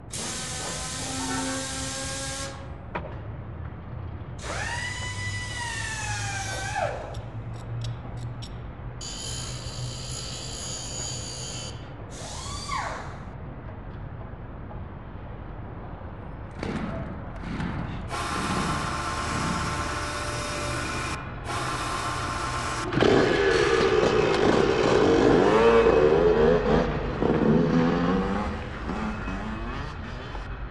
Workshop – motorcycle – dirt bike